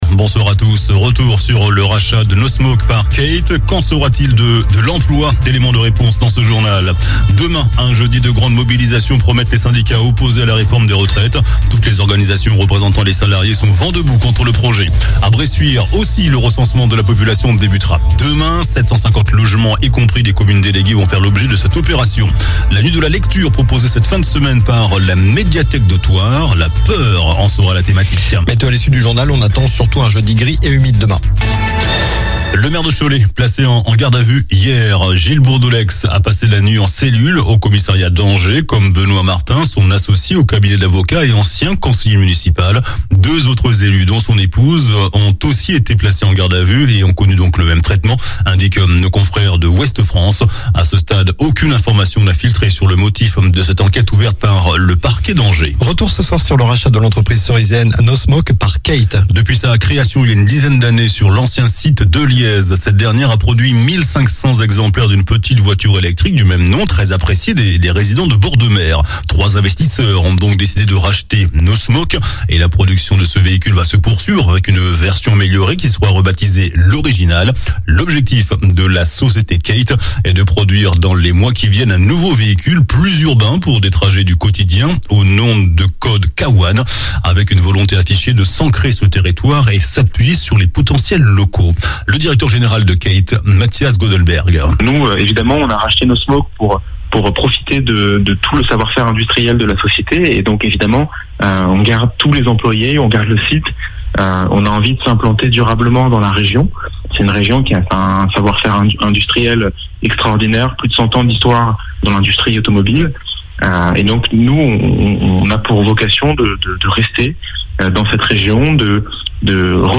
JOURNAL DU MERCREDI 18 JANVIER ( SOIR )